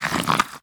assets / minecraft / sounds / mob / fox / aggro4.ogg